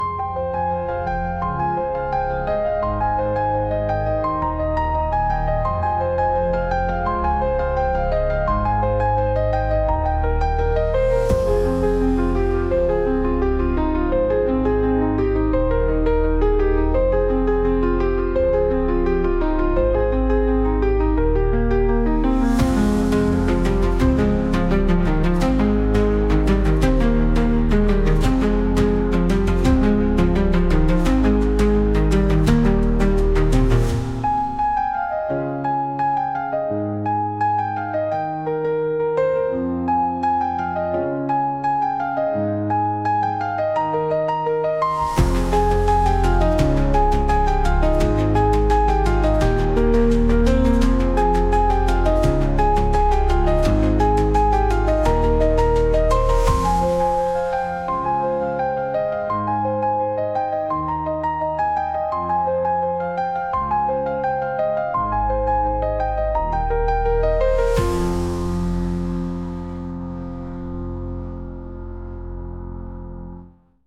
Gli studenti hanno sperimentato con suoni che potessero sembrare alieni e non convenzionali, ma che al contempo riflettessero il tono emotivo della storia.
Possibili colonne sonore